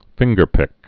(fĭnggər-pĭk)